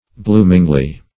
bloomingly - definition of bloomingly - synonyms, pronunciation, spelling from Free Dictionary Search Result for " bloomingly" : The Collaborative International Dictionary of English v.0.48: Bloomingly \Bloom"ing*ly\, adv.